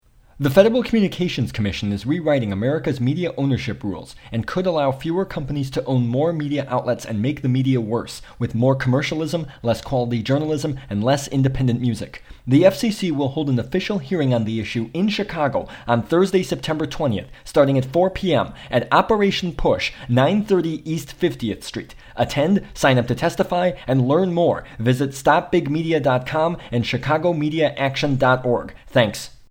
this public service announcement, and providing additional coverage to boot.